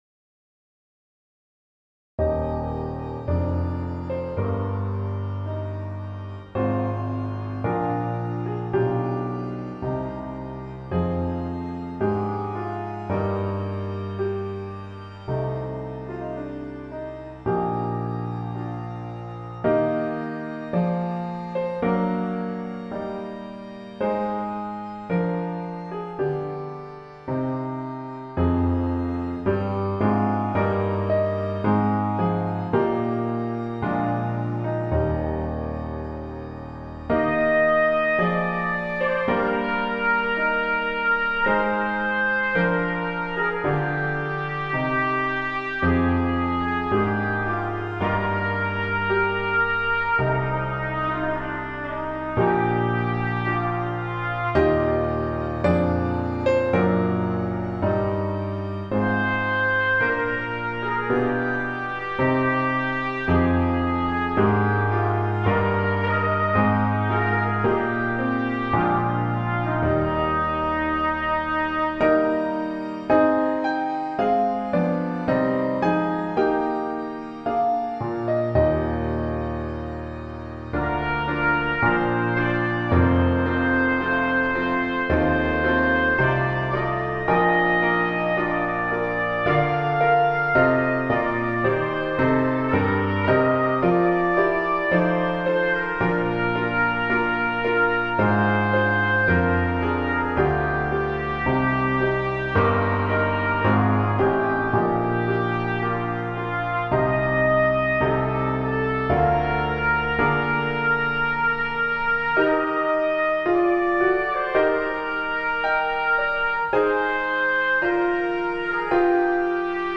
У меня есть проблема с инструментами в Лоджик - инструменты, которые я скачала в Контакт, Омнисфера и прочее - работают только до 2 кГц (примерно) - остальные частоты "провалены" от слова совсем.